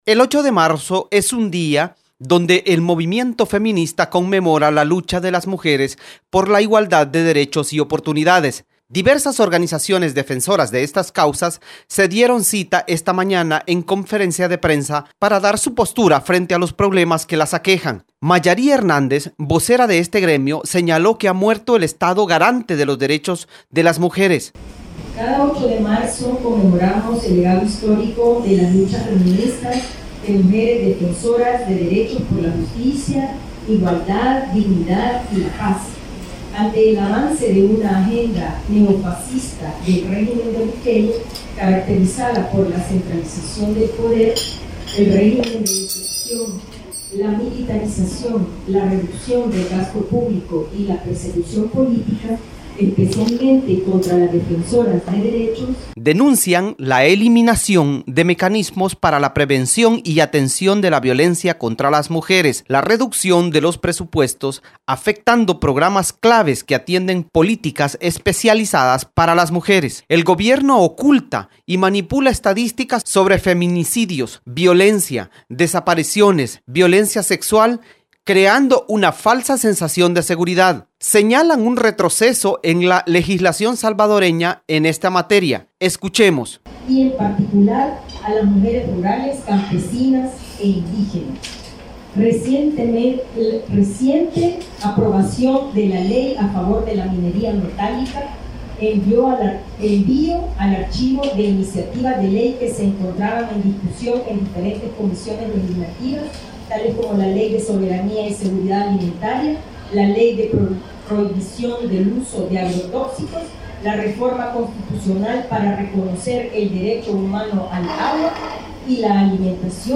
Diversas organizaciones defensoras de estas causas se dieron cita esta mañana en conferencia de prensa para dar su postura frente a los problemas que la aquejan: